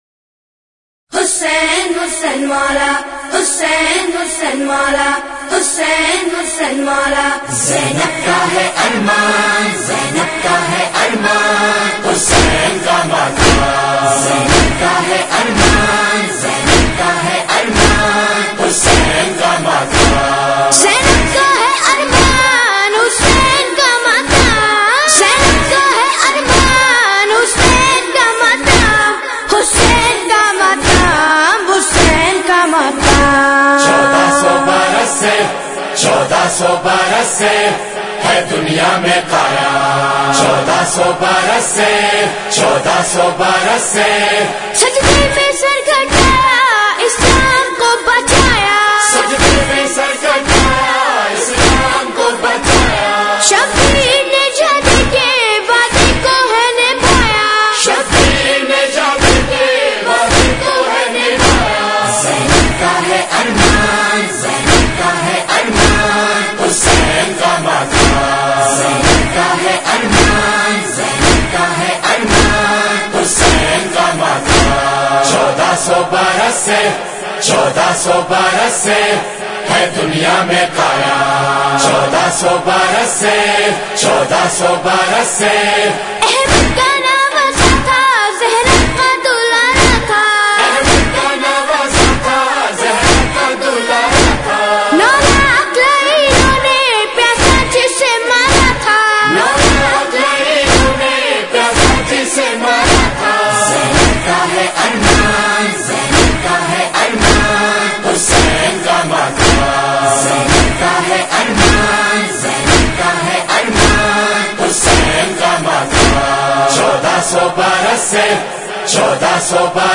All Nohay